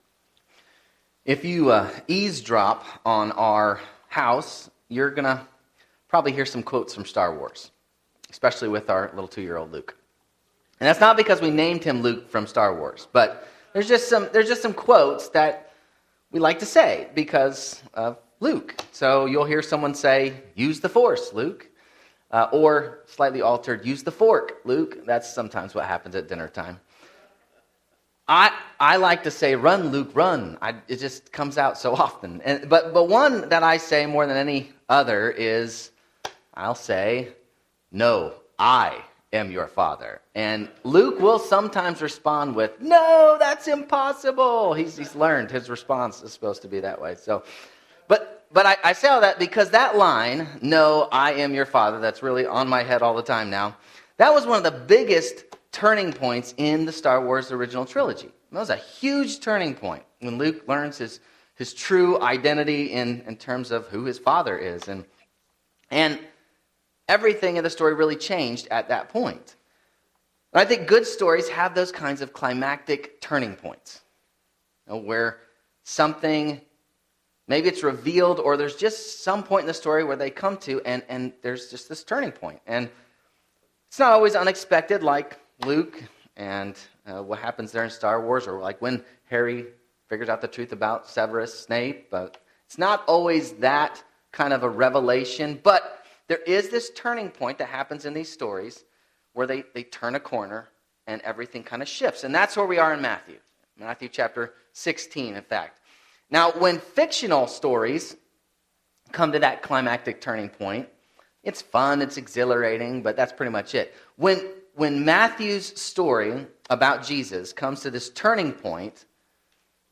Sermons
Genesis 35:1-15 Service Type: Sunday 10:30am